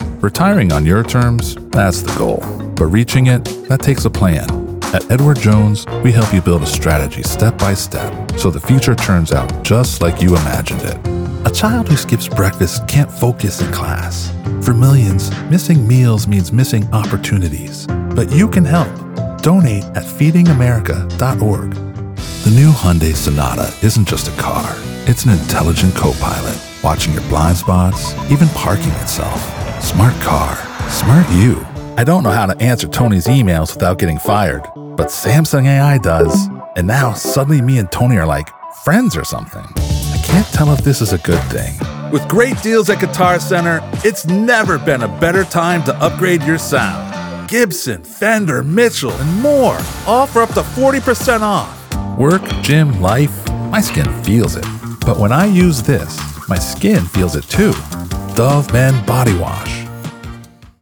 Commercial and Narration Demos
My voice has been described as deep yet dynamic, commanding yet gentle and soothing, allowing me to adapt to a wide range of styles and genres.
I work from my home studio, using a Lewitt LCT 240 Pro microphone, through a PreSonus Audiobox USB96 interface, using Windows 11.